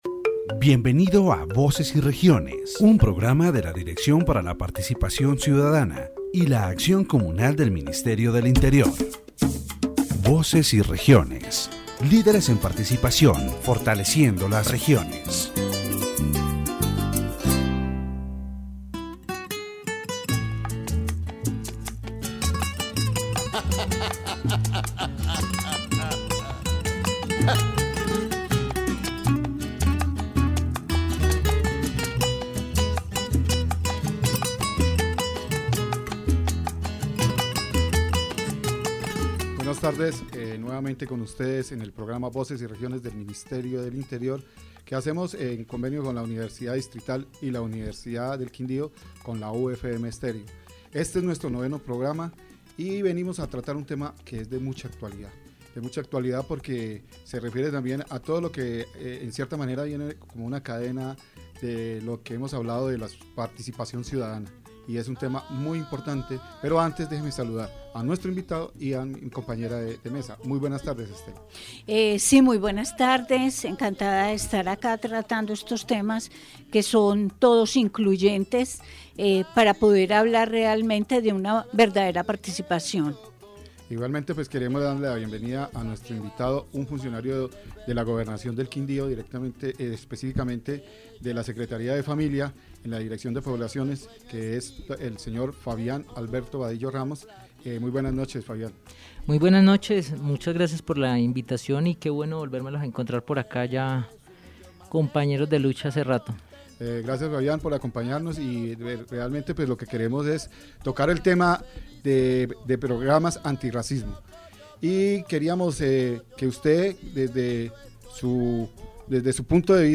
In this section of the Voces y Regiones program, the topic of anti-racism programs and their impact on Colombian society is discussed, with a special focus on the department of Quindío and its capital, Armenia. The interviewee highlights the challenges faced by ethnic minorities in the region, especially those living in vulnerable conditions, and emphasizes that education is a key tool in combating racism and promoting equity.